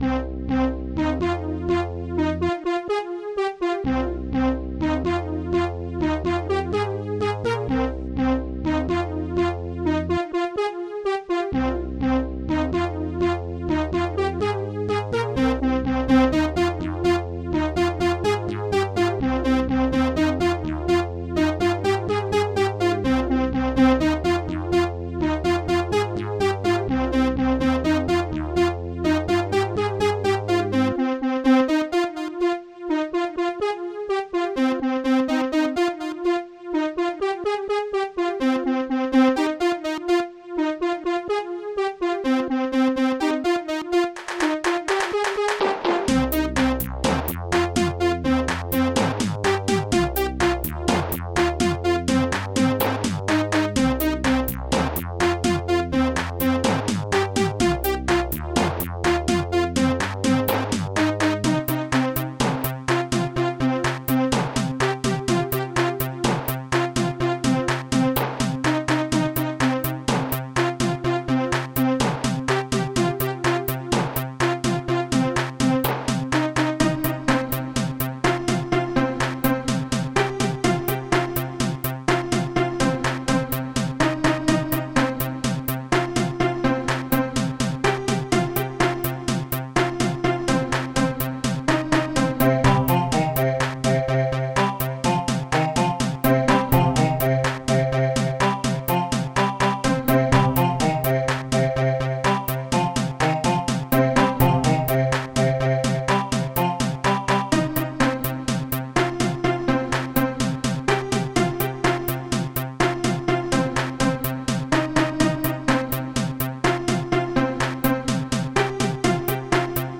space techno music..